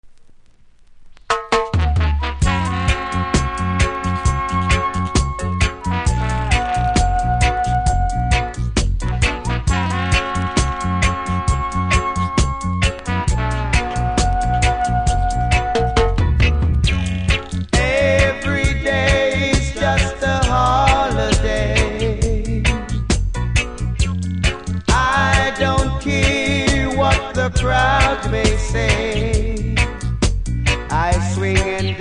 REGGAE 70'S
端から1センチぐらい割れありますが音はプレイ可の良好なので試聴で確認下さい。